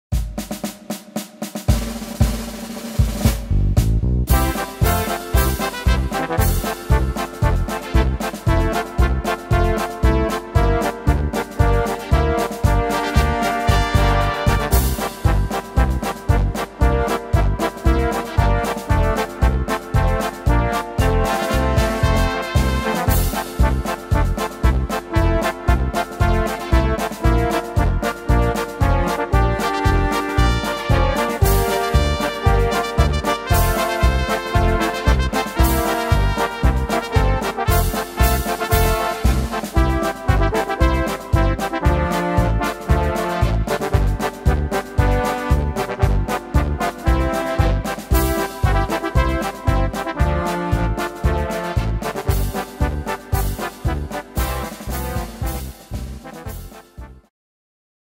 Tempo: 115 / Tonart: F-Dur